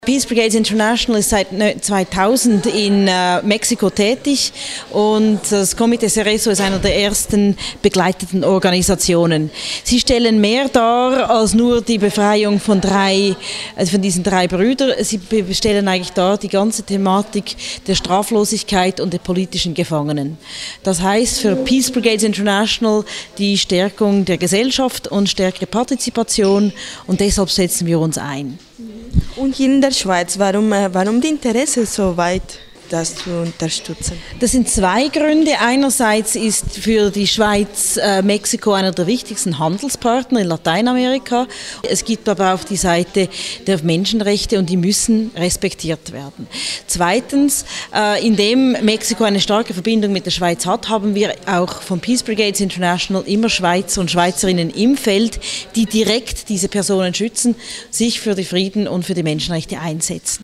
conversa con swissinfo (en alemán)